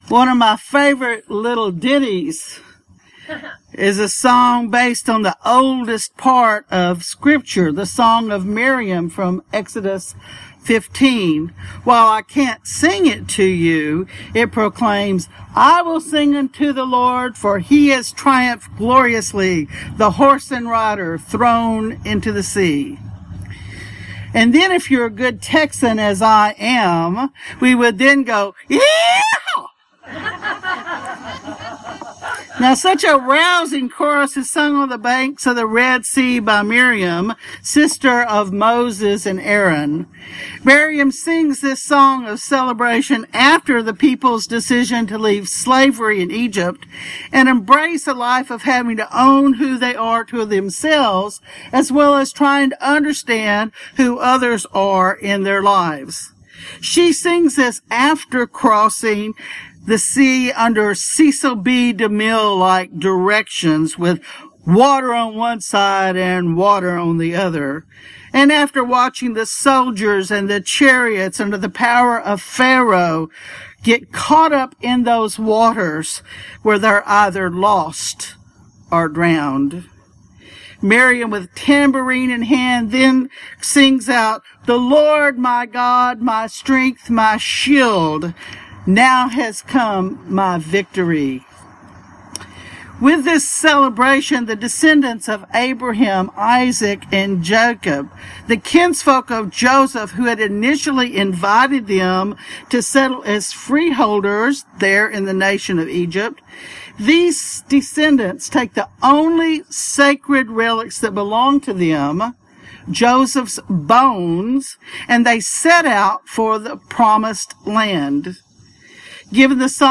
Sermons at Christ Episcopal Church, Rockville, MD
A Time of Transition, Exodus 14:19-31 Sermon